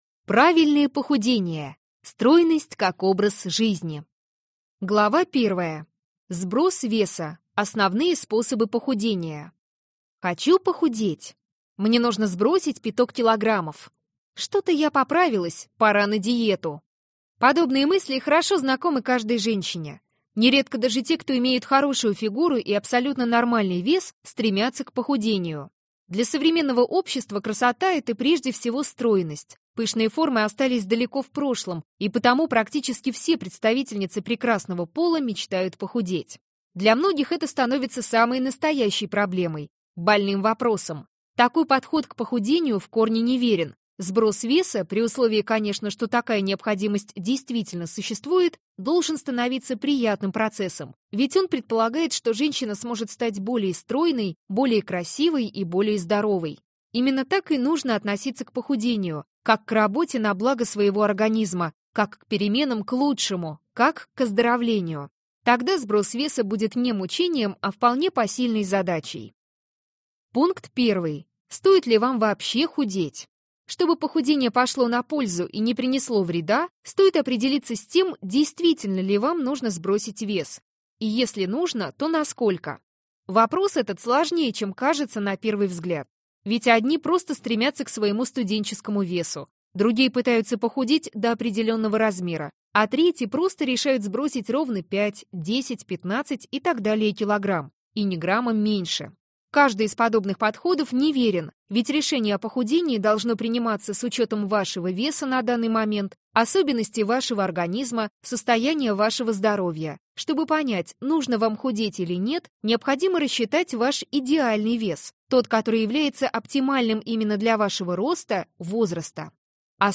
Аудиокнига Как похудеть. 50 секретов стройности | Библиотека аудиокниг